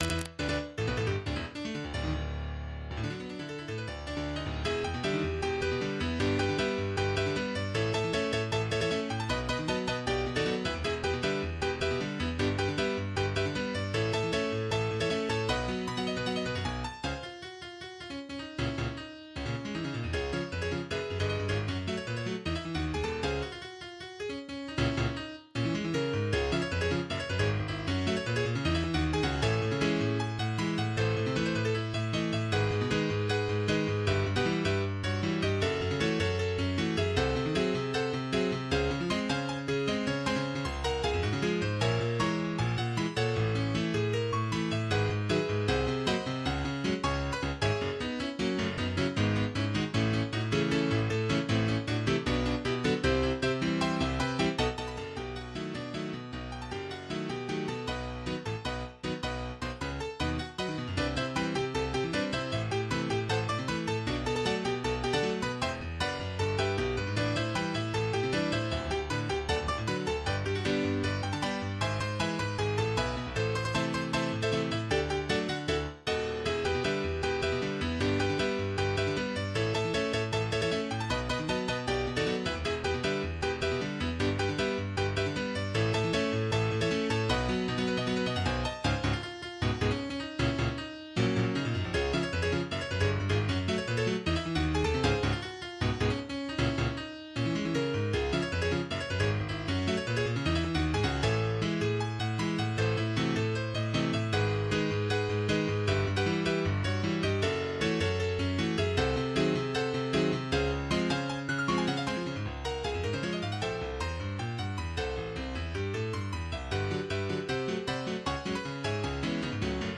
MIDI 54.19 KB MP3